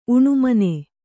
♪ uṇumane